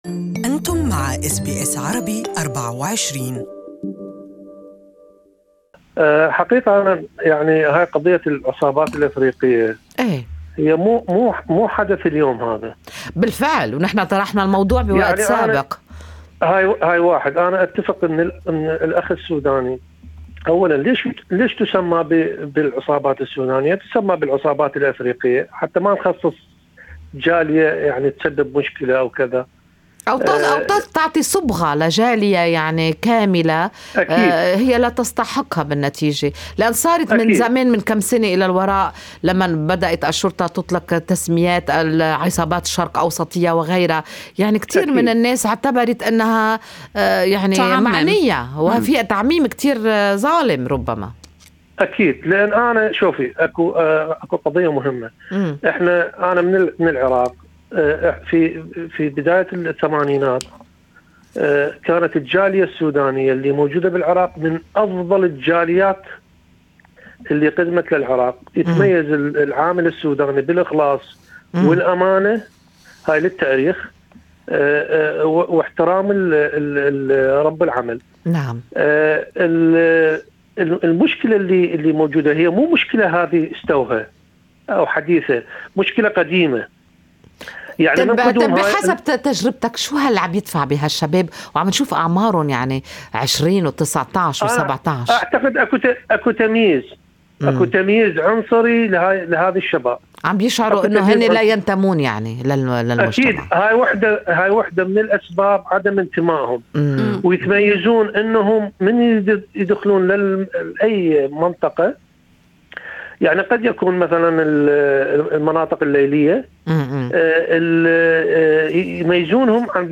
Good Morning Australia discussed the so-called Sudanese Gangs Crisis with listeners, who had different views on the issue.